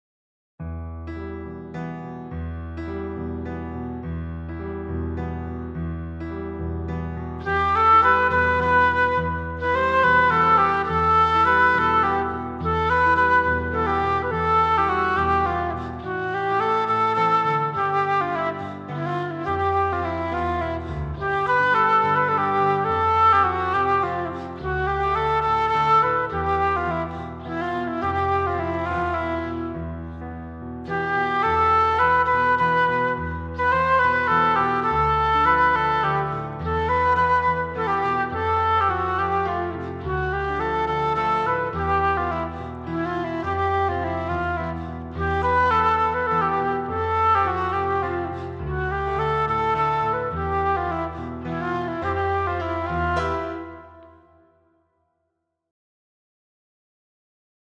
• اجرای تمیز و احساسی با تمپوی استاندارد
• کمک به درک فضای لطیف و غم‌آلود قطعه
ایرانی